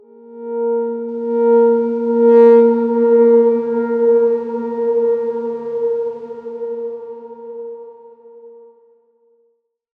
X_Darkswarm-A#3-pp.wav